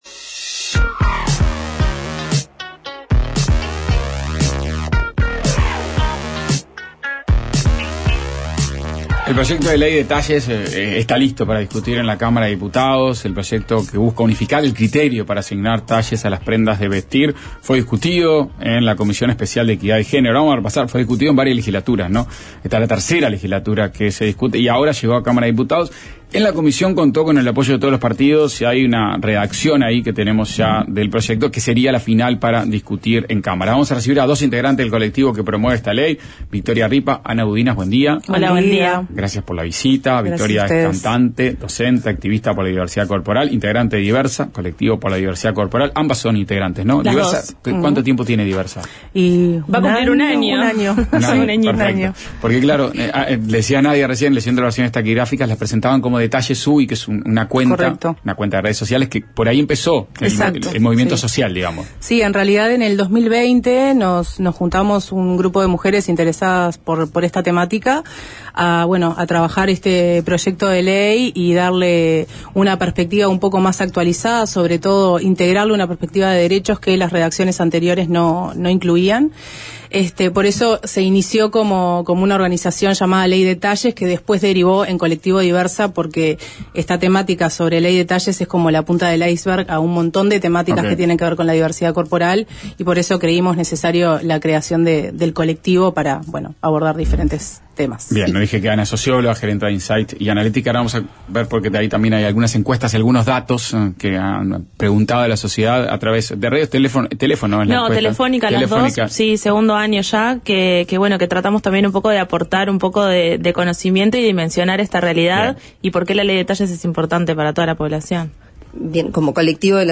Entrevista sobre el proyecto de ley de talles